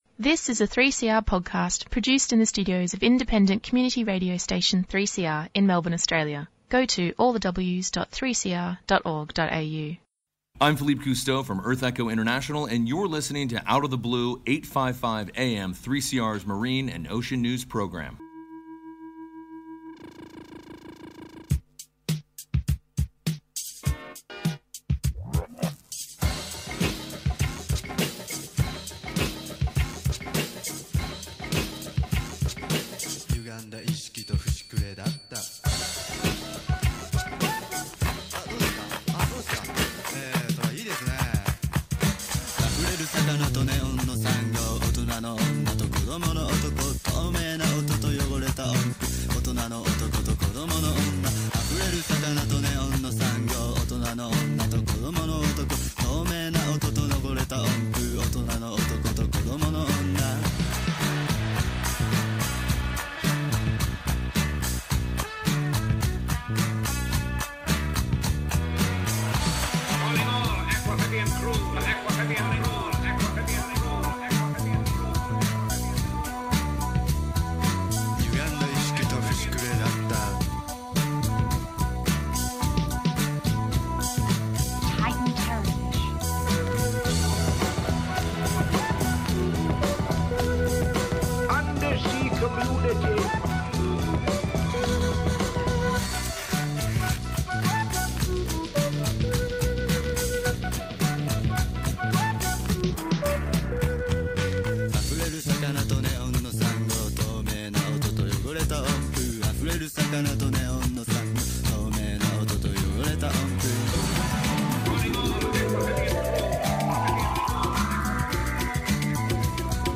Tweet Out of the Blue Sunday 11:30am to 12:00pm Information about marine and coastal environments. News and interviews with marine scientists, campaigners and conservation workers.